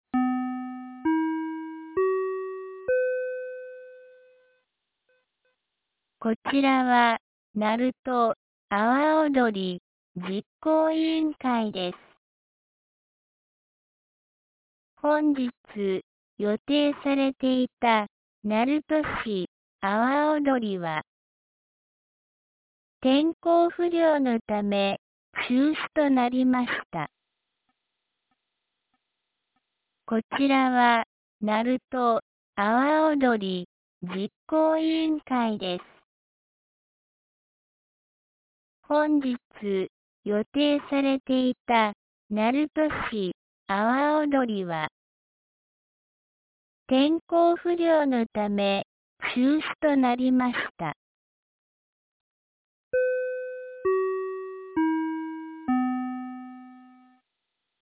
2025年08月10日 18時30分に、鳴門市より全地区へ放送がありました。